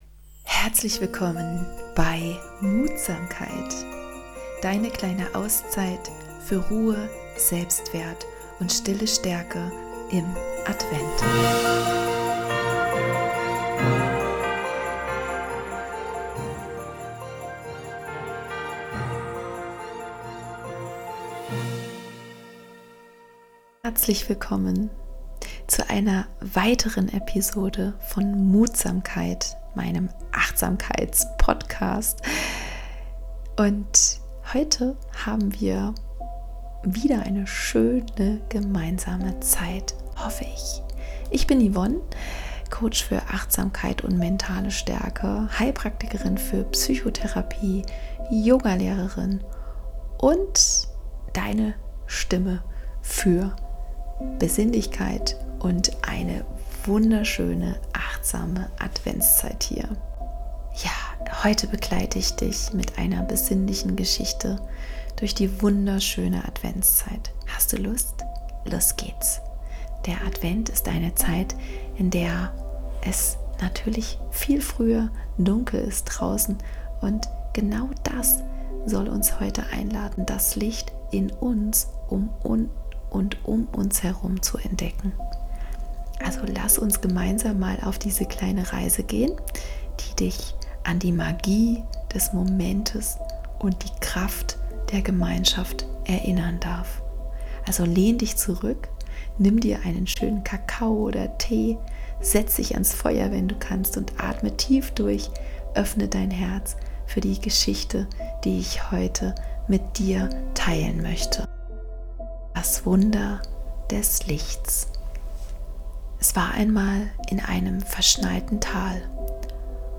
eine Fantasiereise voller Wärme und Adventsstimmung.
Diese Folge schenkt dir Momente der Stille, des Nachspürens und